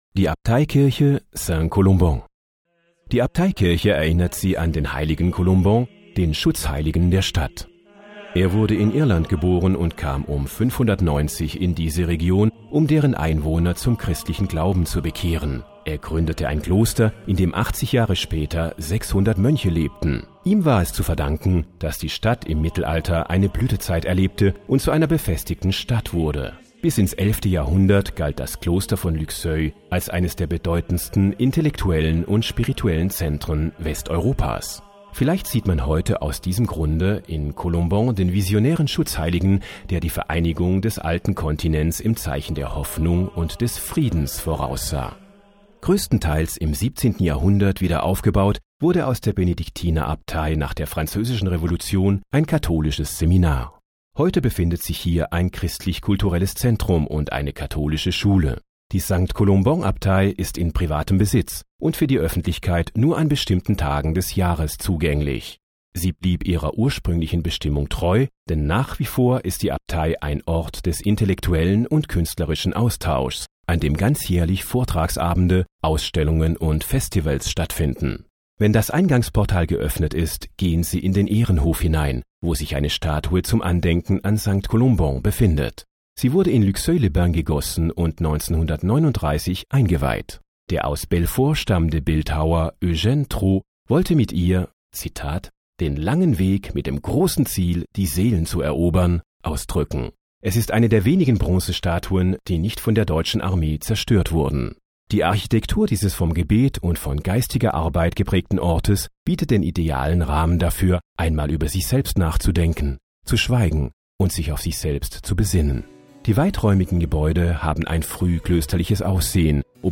Balade Audio – 07 Abbaye Saint-Colomban
07_die_abteikirche_saint_colomban_de.mp3